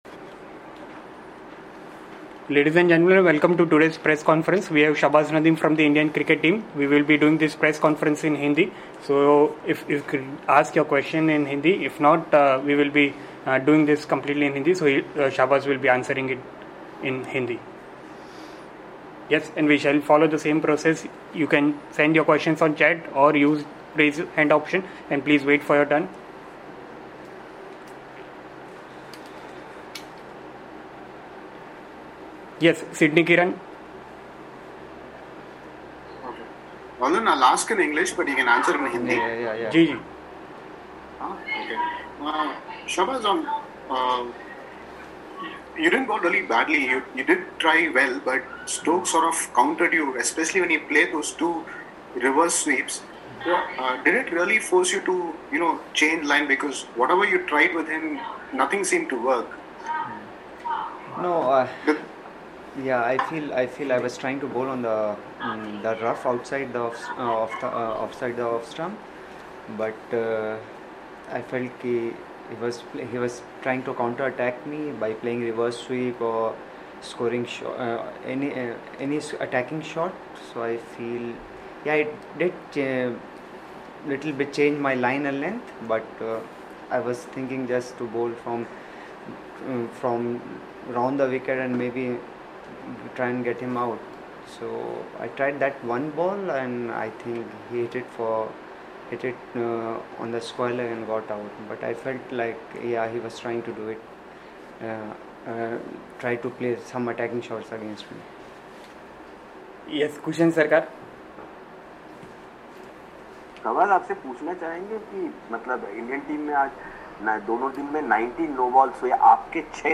Shahbaz Nadeem addressed a virtual press conference after the second day’s play of the 1st Paytm India-England Test in Chennai.